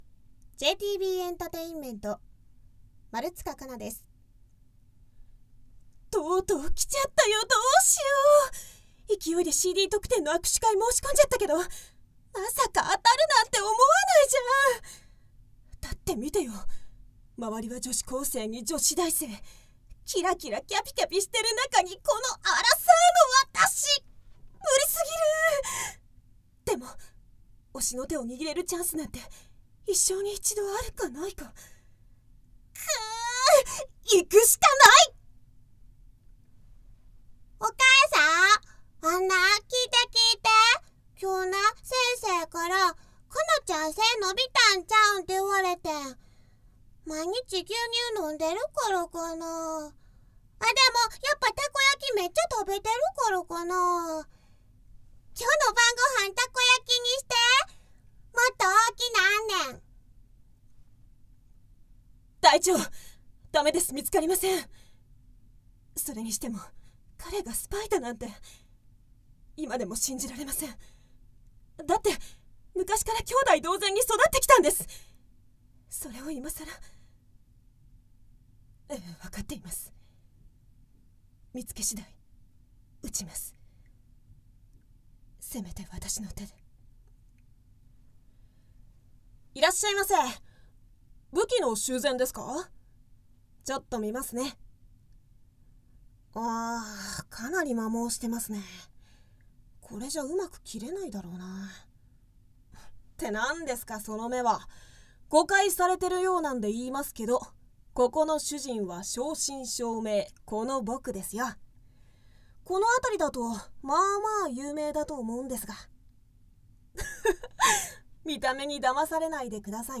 方　言　：　兵庫県
ボイスサンプル